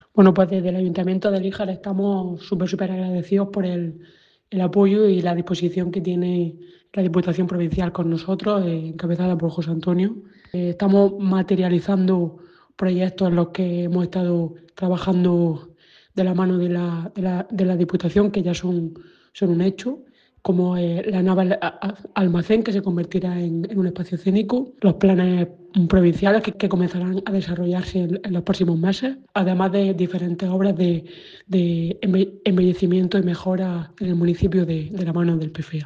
Alcaldesa-de-Lijar.mp3